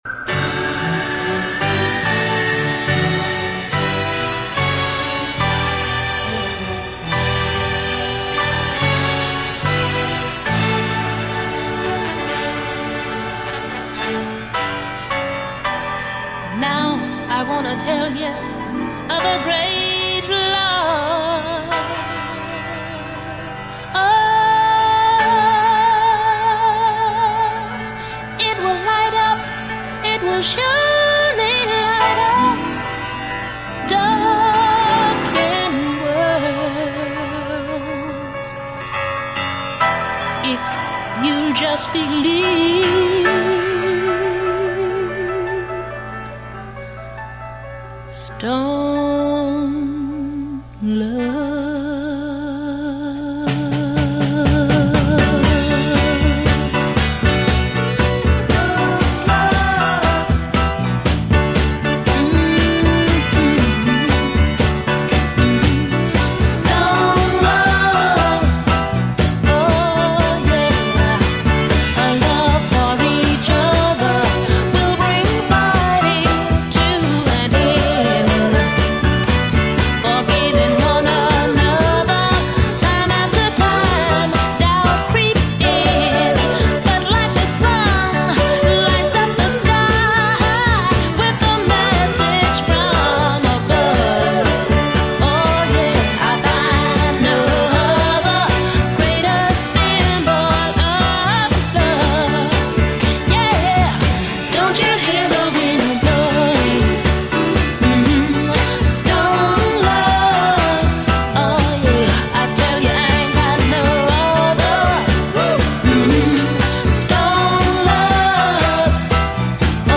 pop/soul 7″